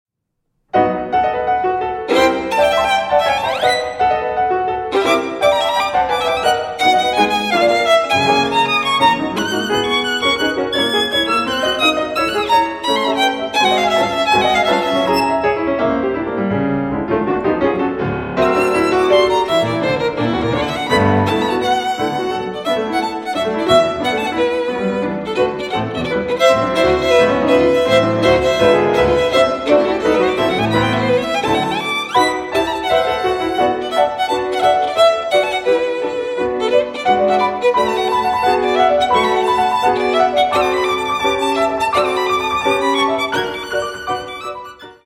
Allegro scherzando (7:27)